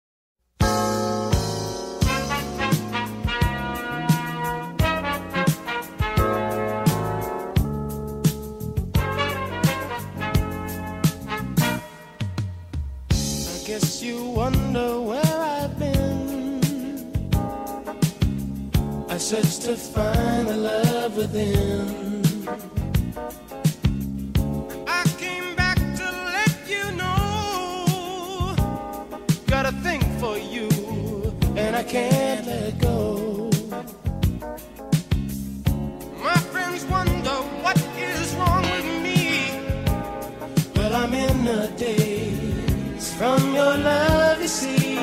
RnB & Garage